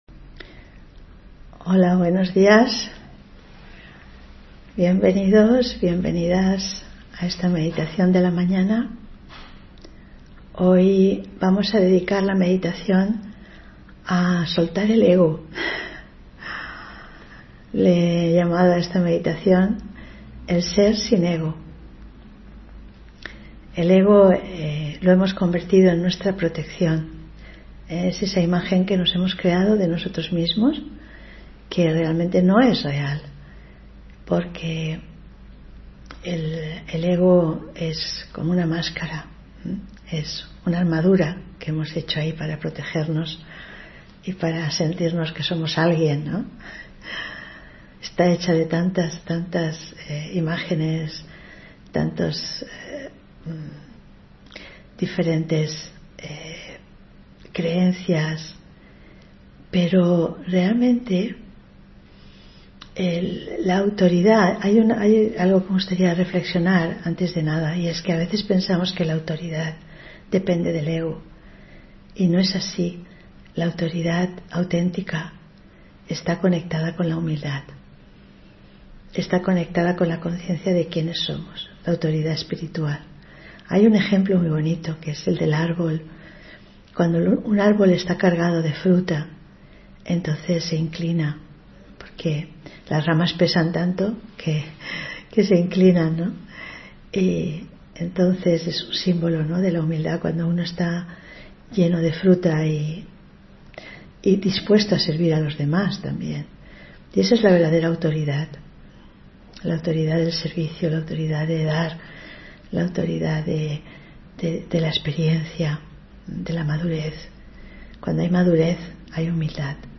Meditación y conferencia: Asiéntate y da un salto en tu vida (13 Marzo 2024)